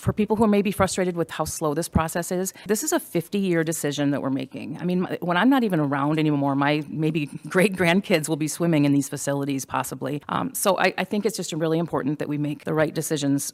The vote is on a 68-million dollar aquatics bond, to replace two pools and make improvements at the recently purchased west side Recreation Center. City Councilor Jennifer Sigette.